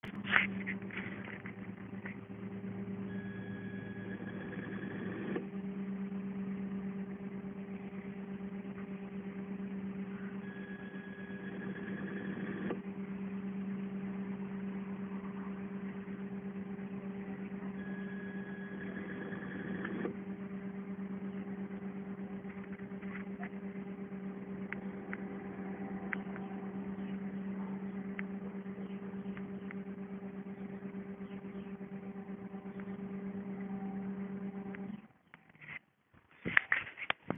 Mon problème n'est pas solutionné mais a évolué car auparavant le compresseur ne donnait aucun signe de vie alors que maintenant j'entends qu'il veut démarrer, tente 3 fois le démarrage et se met en veille et à peu près 5 minutes plus tard il réessaie.
demarrage.mp3